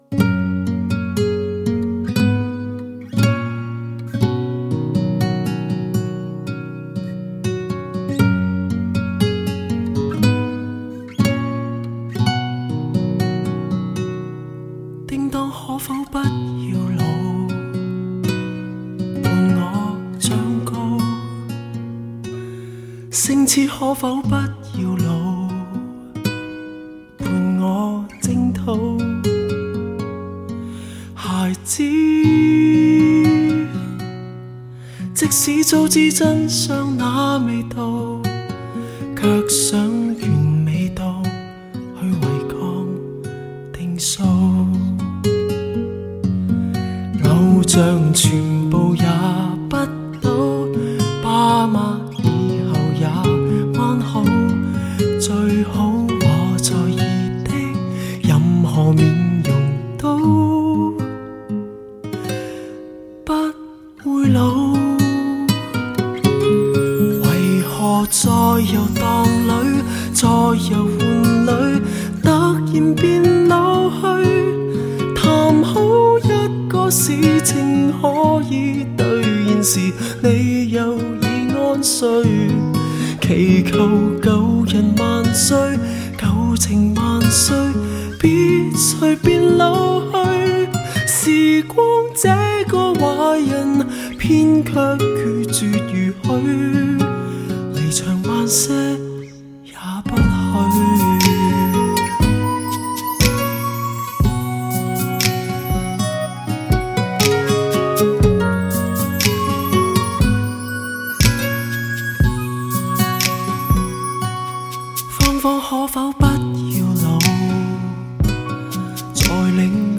既然要向那个时代致敬，就连录音器材，也是亲自在网上竞投一些旧机回来，希望将那时代的声音，原原本本地带到2014年。“